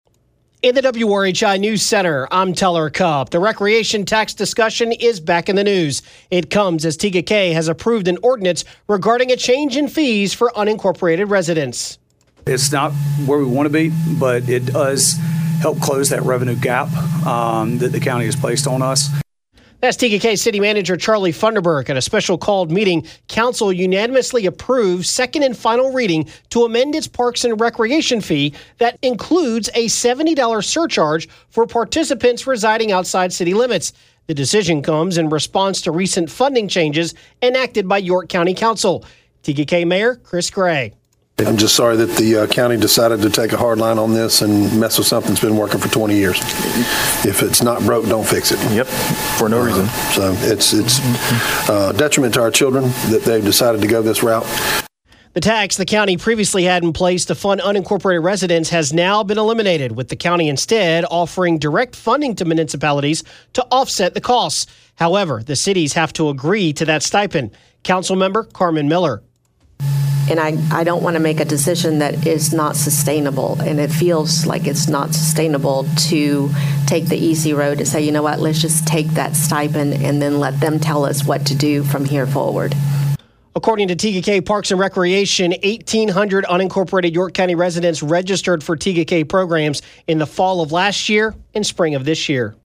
AUDIO: Reaction from Tega Cay leaders as they make the final vote on recreation fees for unincorporated residents